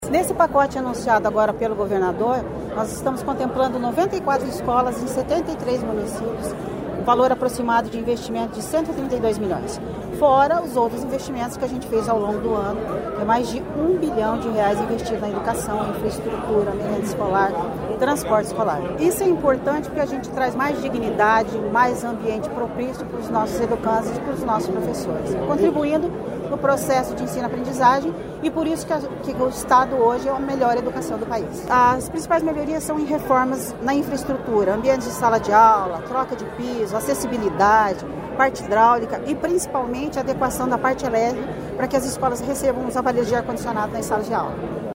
Sonora da diretora-presidente do Fundepar, Eliane Teruel Carmona, sobre as obras anunciadas em 94 escolas estaduais do Paraná